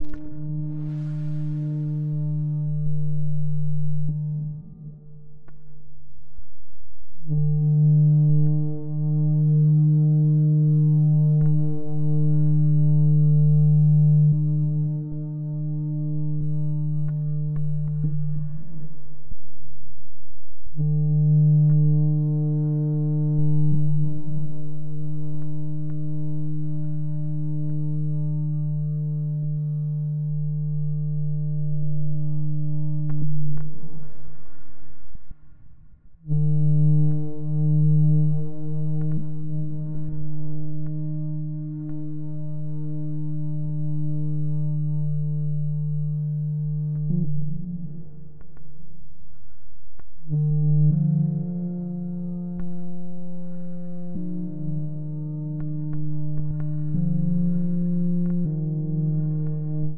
Fog Horn | Sneak On The Lot
Fog Horn distant horn with revere and pitch modulations